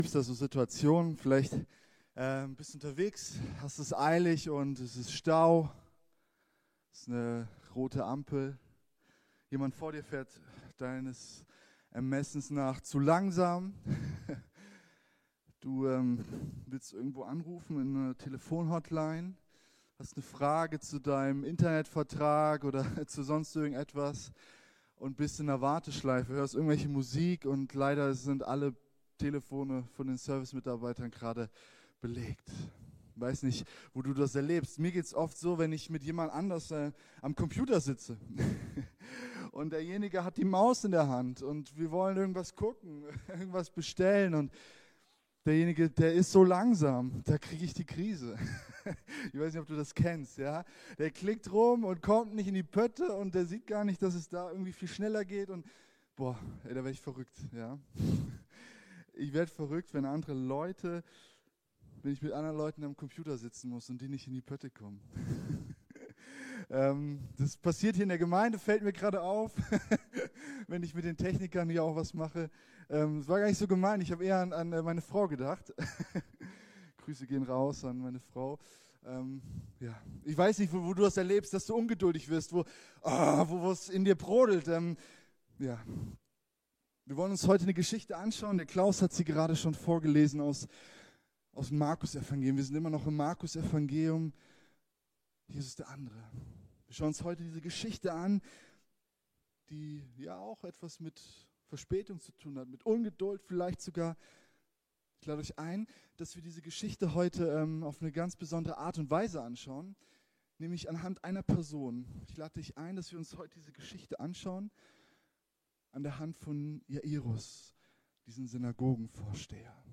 Predigtserie Jesus der andere – FeG Waigandshain